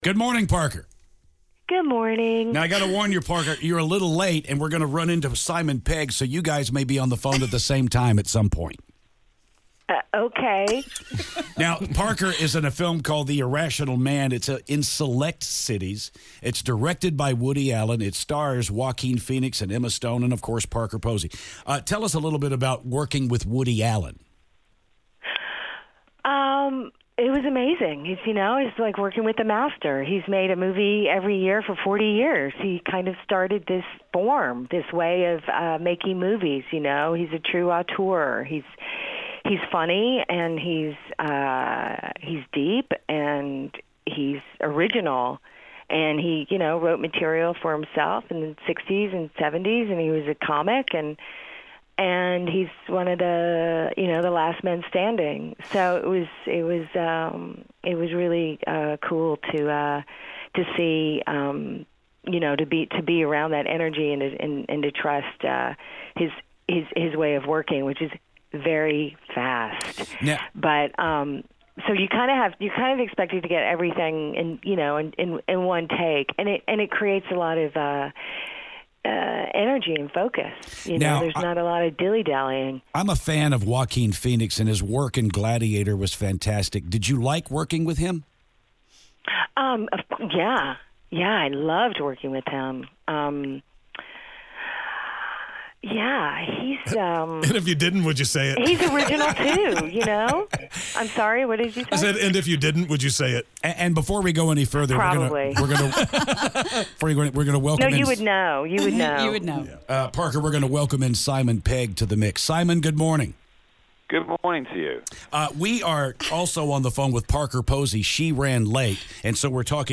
Parker Posey/Simon Pegg Interview
Parker Posey was late and we had to interview Simon Pegg, so we did them both at the same time. Somehow it worked out!